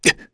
Lusikiel-Vox_Damage_kr_01.wav